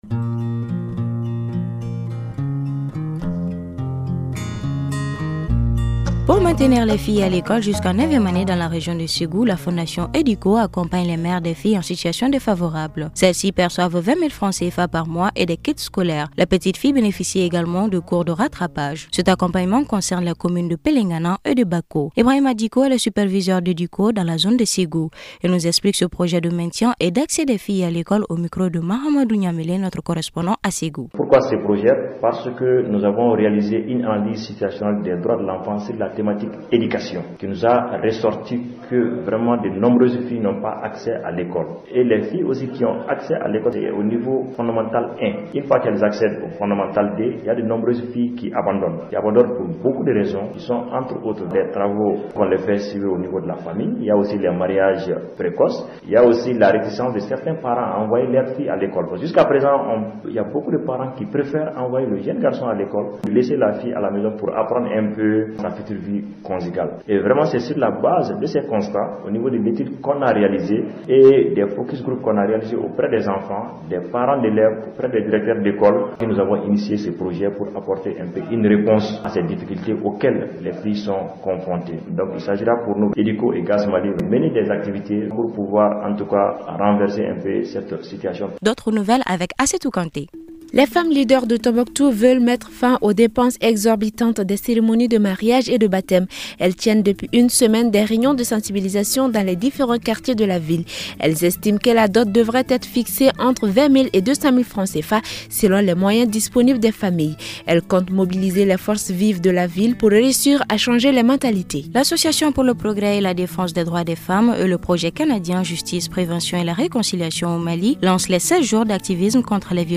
Le Magazine toutes les femmes du Mali est présenté par